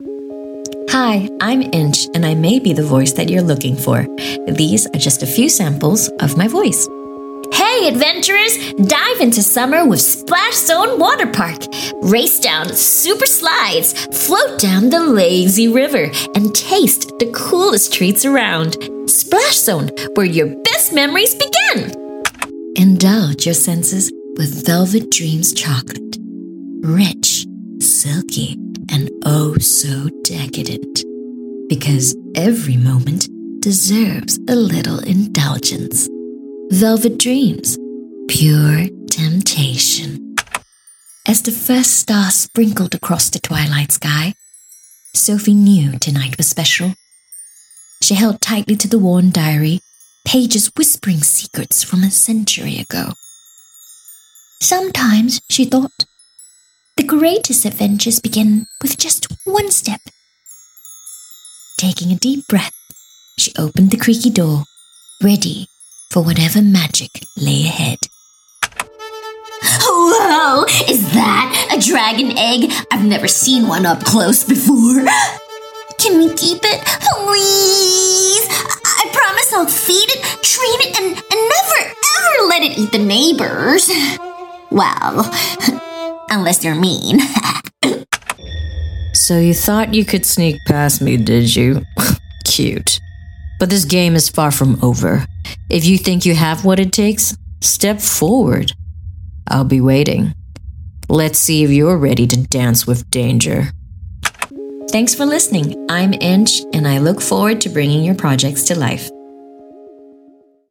Voice Samples: VoiceReel
female
EN Asian